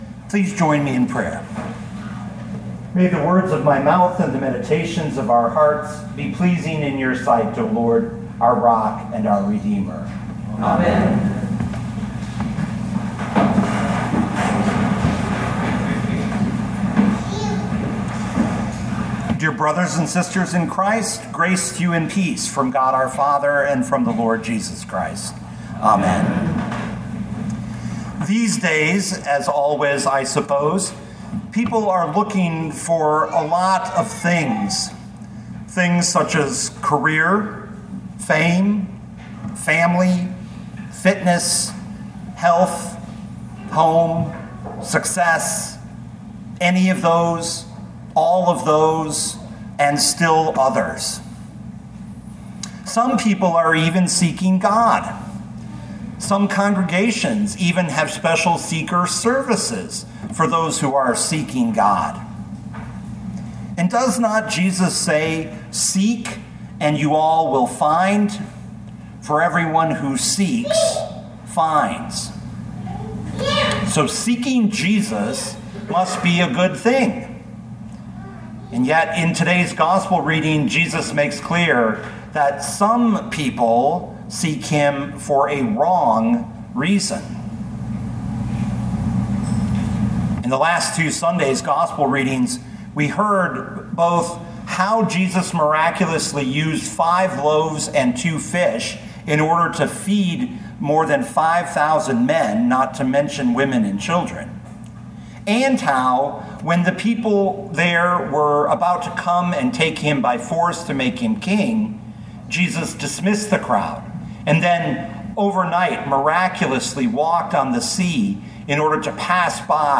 2024 John 6:22-35 Listen to the sermon with the player below, or, download the audio.